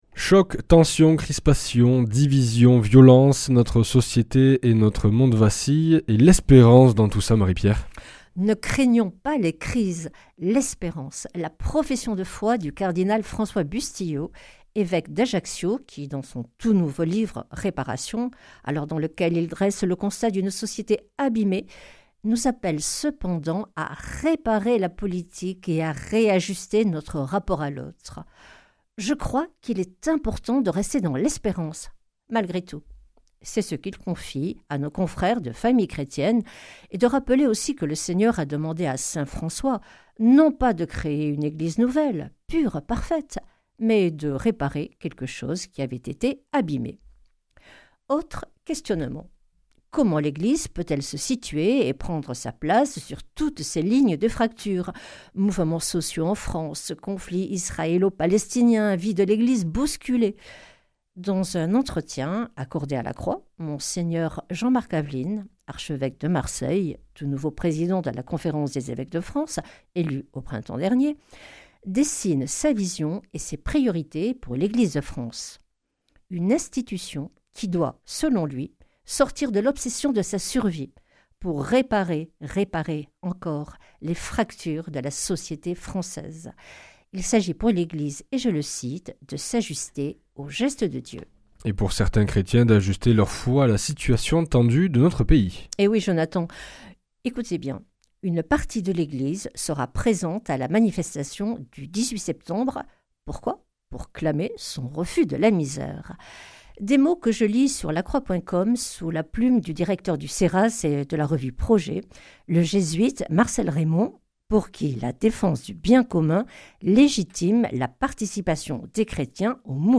Revue de presse
Une émission présentée par